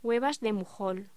Locución: Huevas de mujol
voz